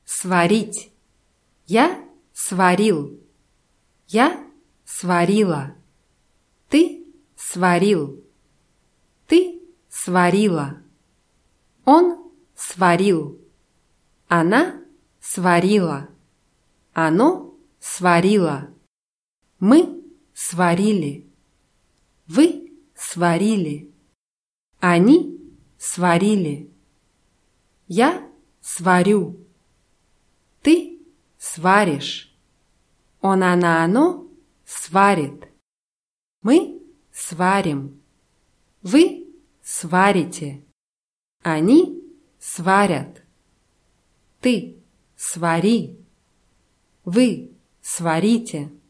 сварить [swarʲítʲ]